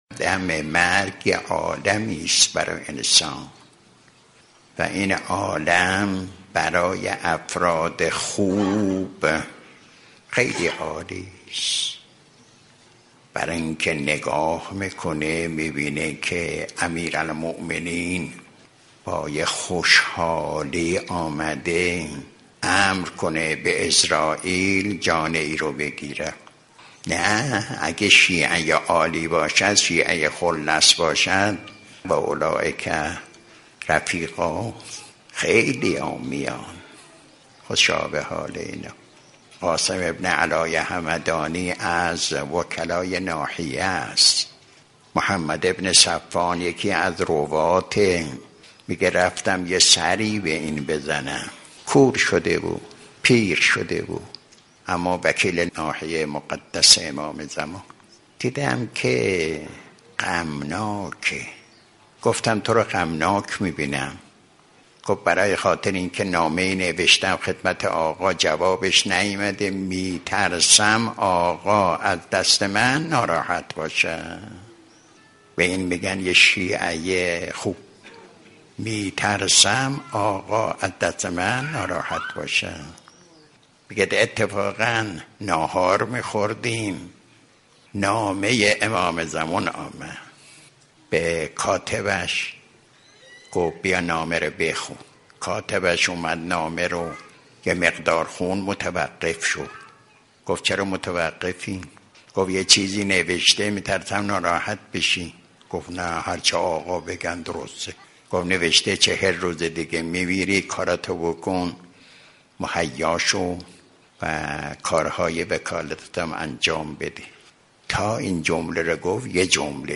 به گزارش خبرگزاری حوزه، آیت الله مظاهری در یکی از دروس اخلاق خود به موضوع «احوالات شیعه واقعی در هنگام مرگ» پرداختند که تقدیم شما فرهیختگان می شود.